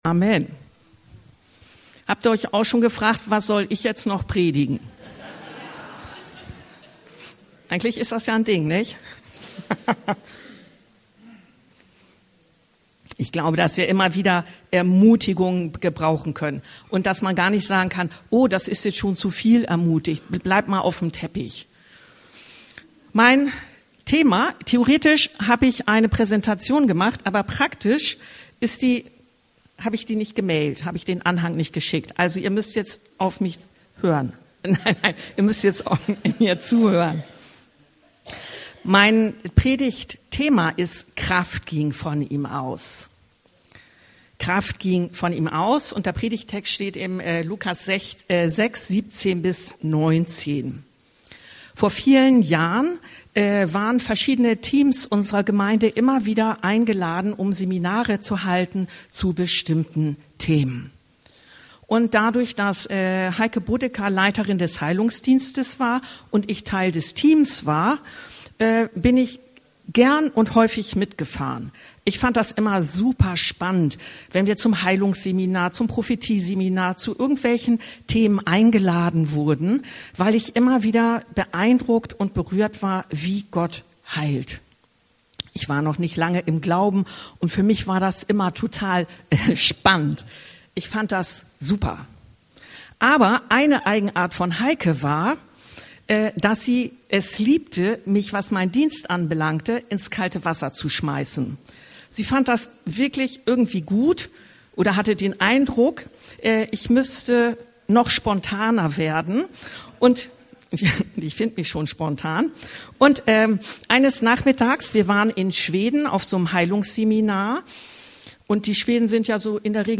Kraft ging von ihm aus - Lukas 6,17-19 ~ Anskar-Kirche Hamburg- Predigten Podcast